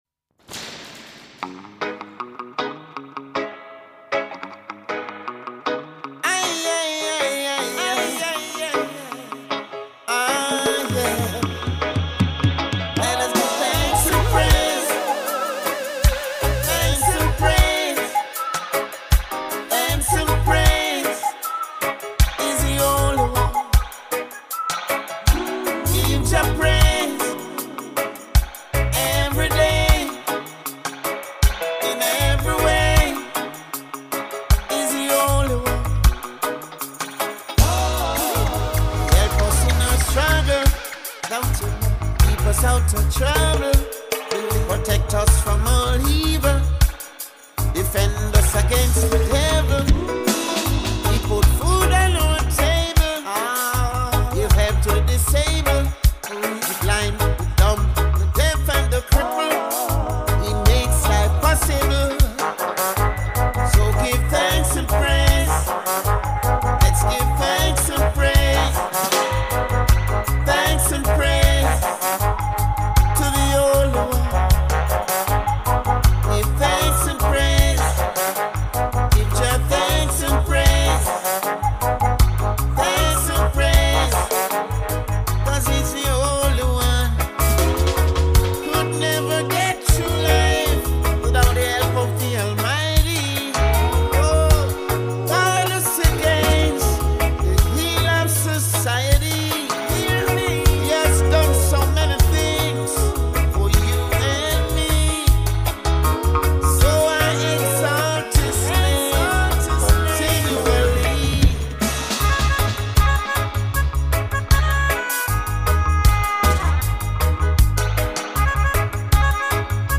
with extended and dub versions